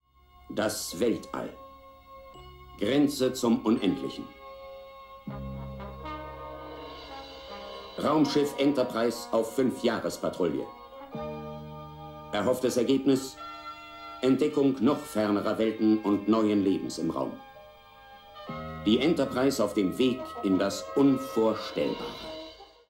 spricht den Prolog-Text der ZDF-Synchronfassung von Star Trek: Die Abenteuer des Raumschiff Enterprise.